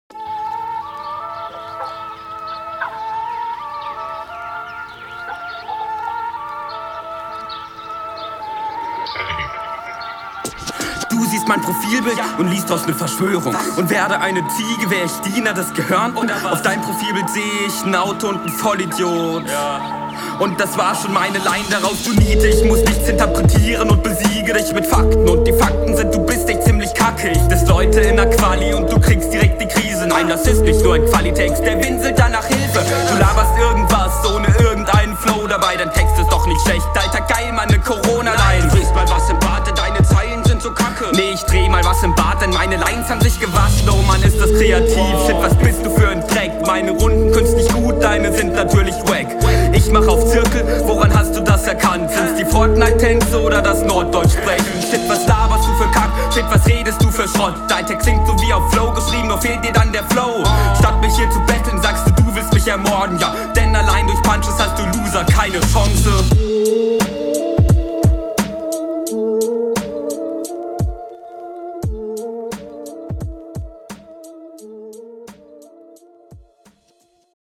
Sound ist auf dem gleichen Level wie bei deinem Gegner.